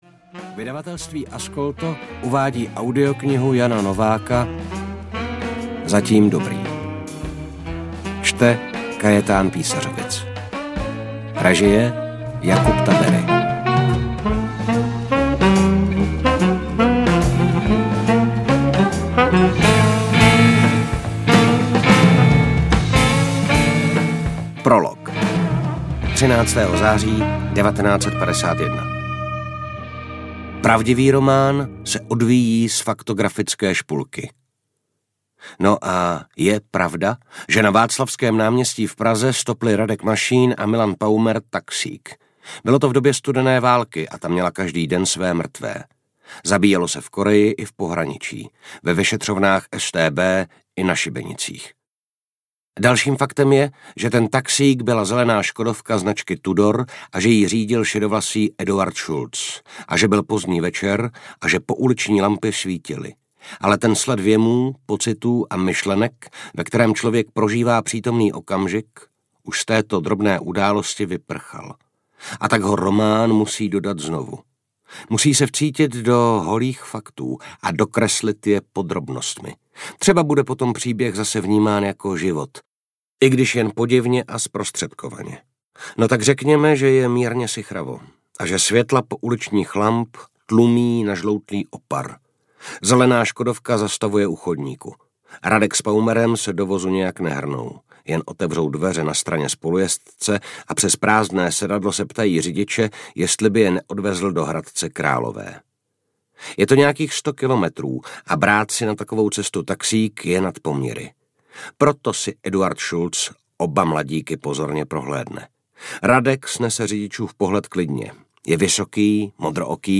Zatím dobrý audiokniha
Ukázka z knihy
Natočeno v roce 2025 ve studiu 5Guests.